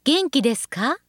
ホームページ作成で利用できる、さまざまな文章や単語を、プロナレーターがナレーション録音しています。